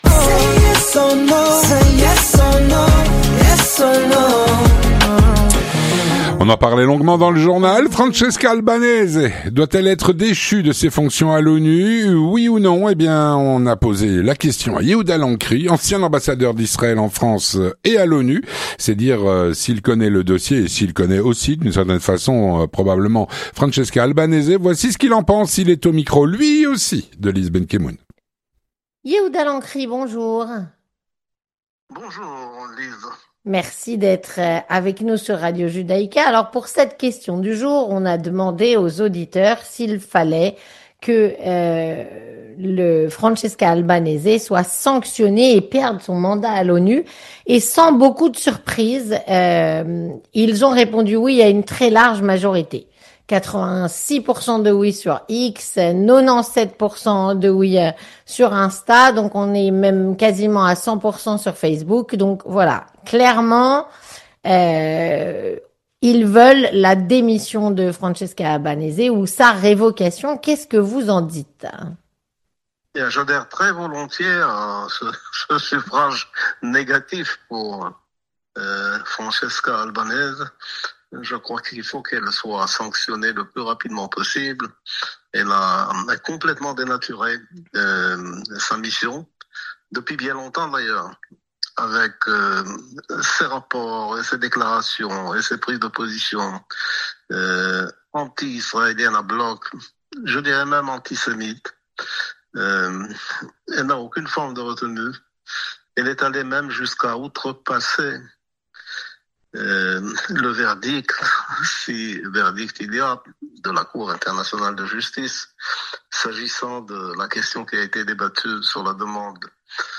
Yehuda Lancry, ancien ambassadeur d’Israël en France et à l’ONU, répond à la "Question Du Jour".